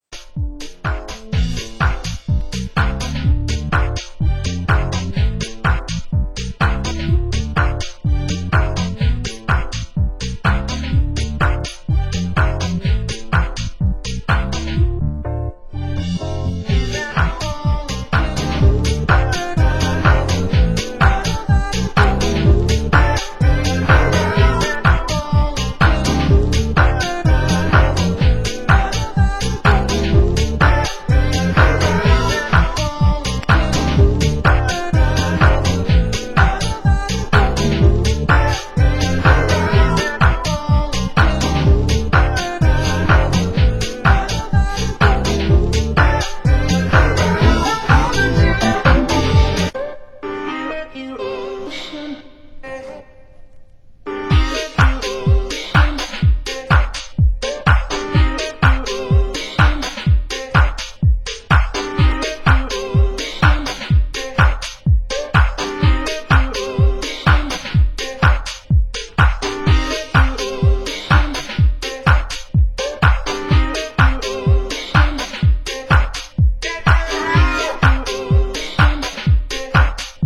Format: Vinyl 12 Inch
Genre: UK Garage